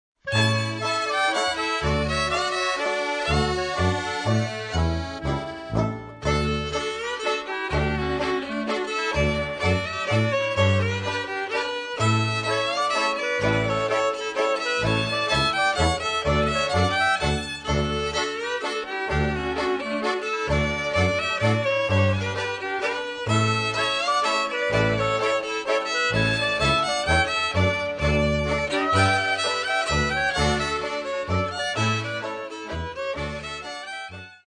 husle, trúbka, spev
klarinet, spev
banjo, drumbľa, spev
akordeón, spev
tuba, spev
Nahrané a zostrihané analógovou technikou.